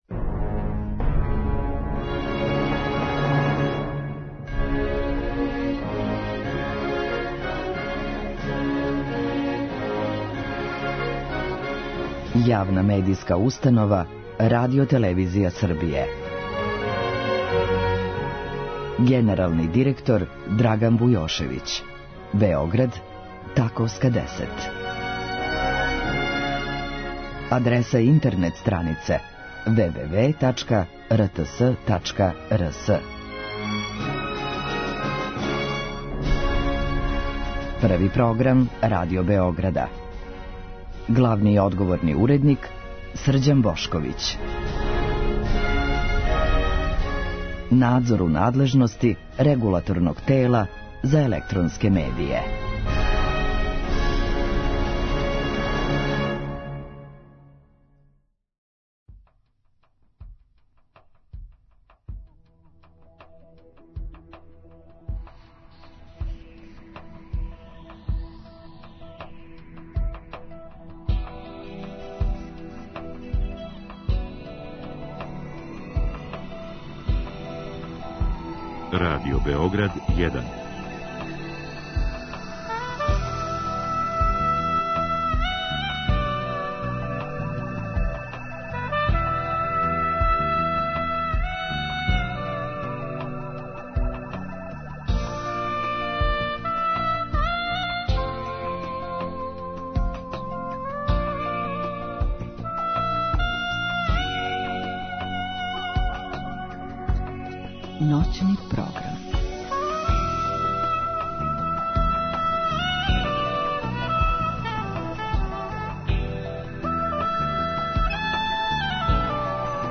У другом сату наше телефонске линије биће отворене за слушаоце који ће у директном програму моћи да поставе питање госту.